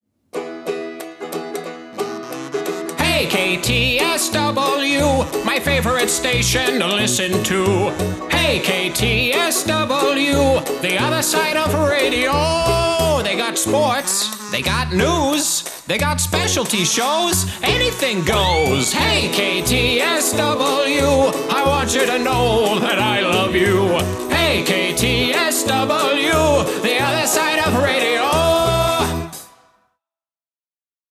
This is a general station promo. I wrote and performed the original music.